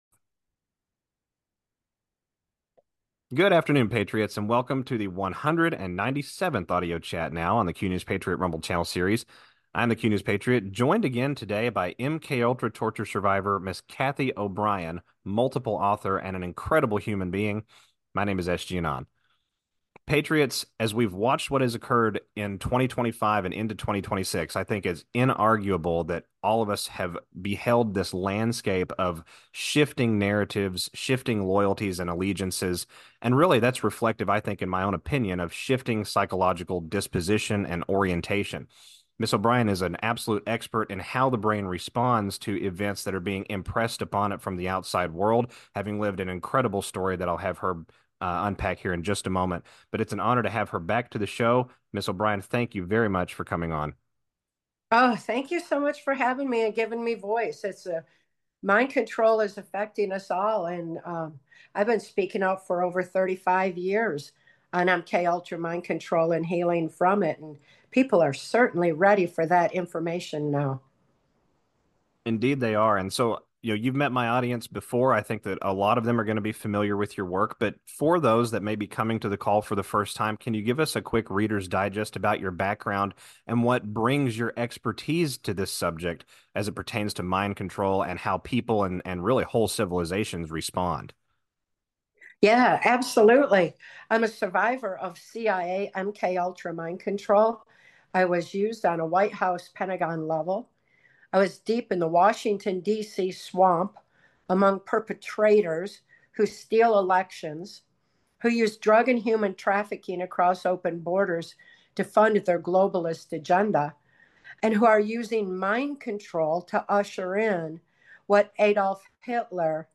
AUDIO CHAT 197